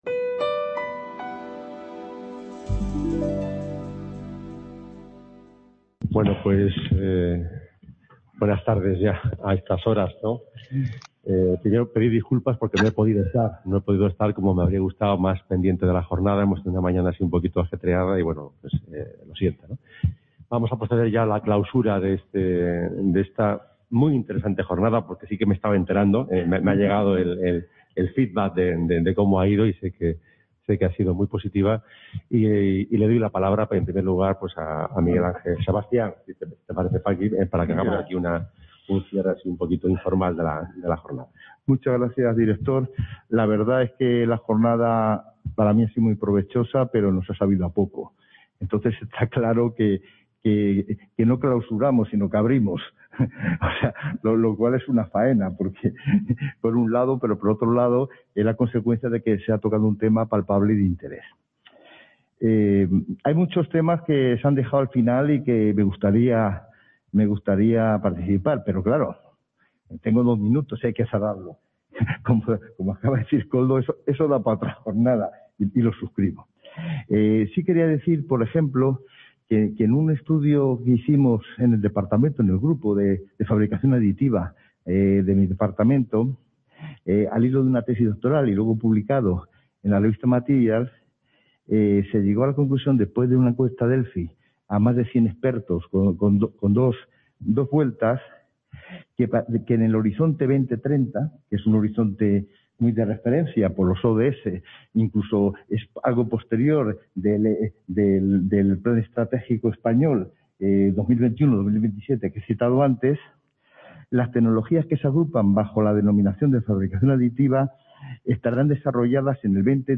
CA Ponferrada - Inauguración del Curso Académico 2020/2021 en el Centro Asociado a la UNED en Ponferrada.